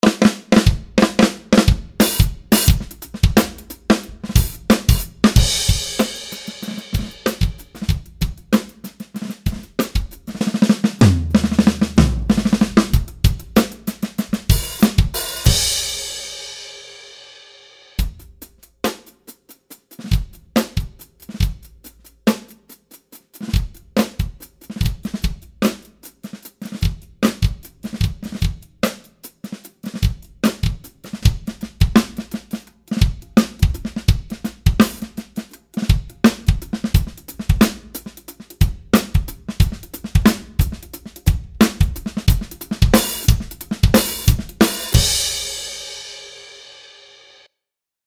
Crisp and tasty beats with a dusty, lo-fi, boom bap vibe.